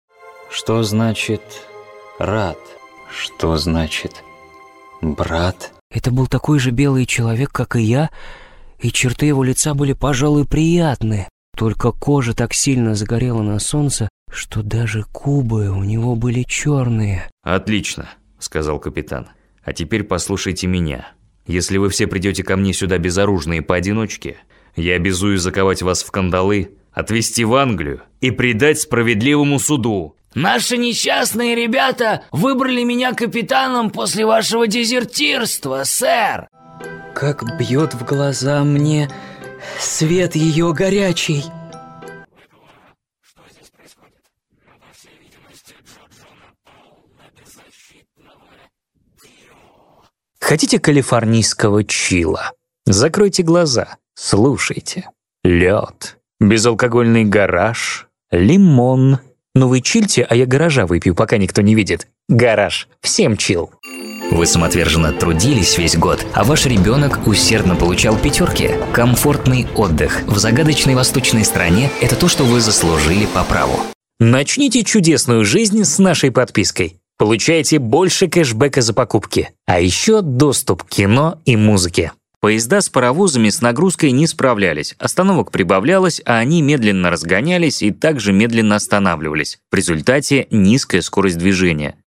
Мужской